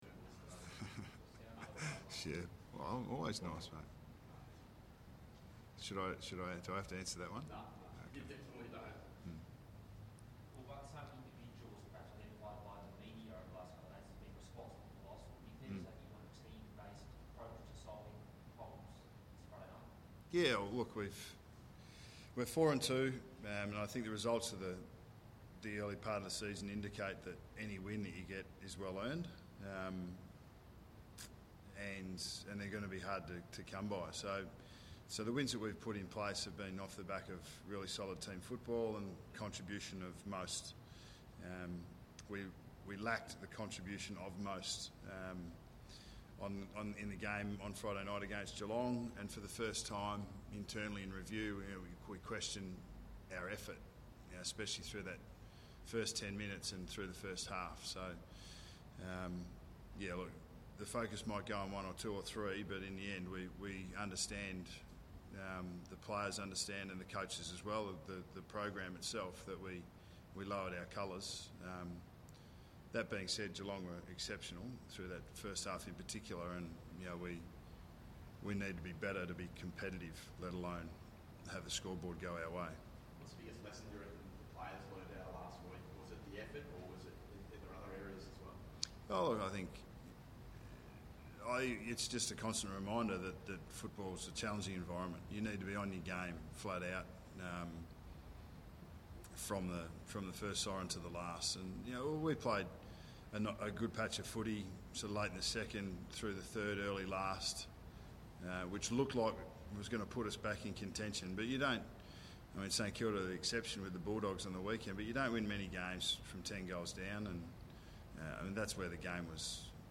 Listen to Nathan Buckley's mid-week press conference at the Westpac Centre on Tuesday 12 May 2015.